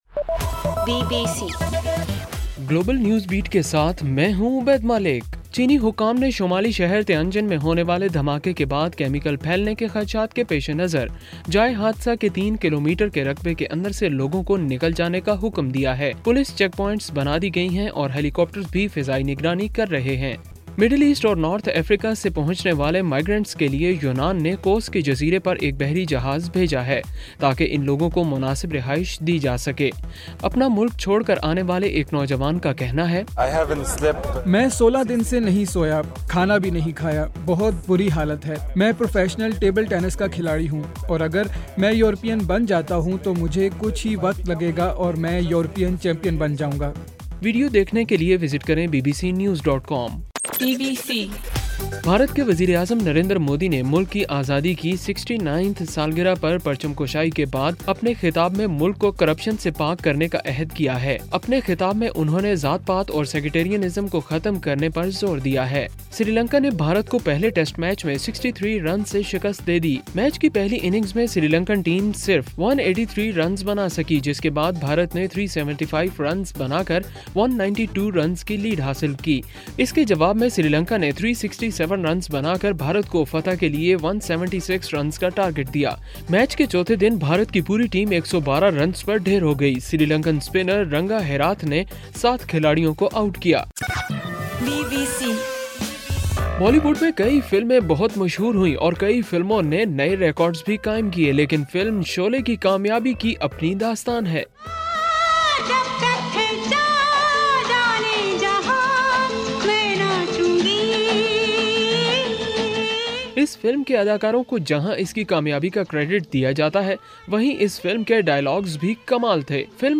اگست 15: رات 8 بجے کا گلوبل نیوز بیٹ بُلیٹن